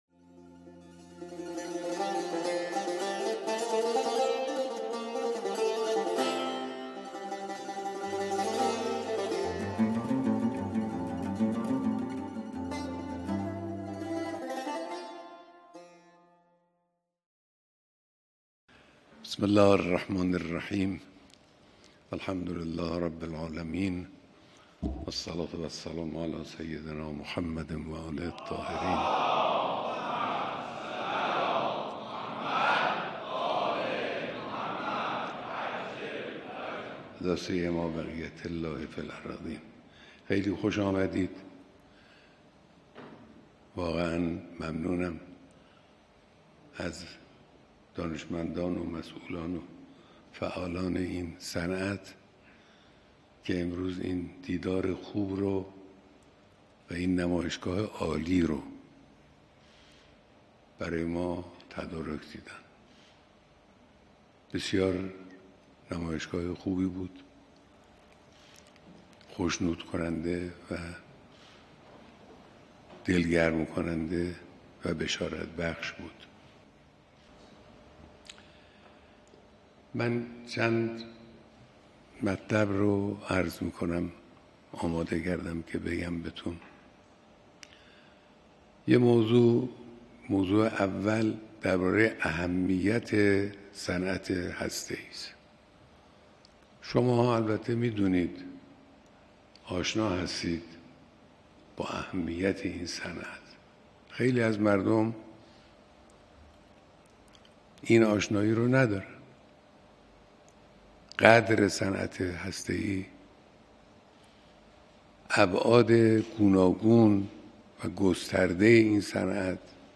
بیانات در دیدار دانشمندان، کارشناسان و مسئولان صنعت هسته‌ای کشور